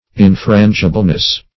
Search Result for " infrangibleness" : The Collaborative International Dictionary of English v.0.48: Infrangibleness \In*fran"gi*ble*ness\, n. The state or quality of being infrangible; infrangibility.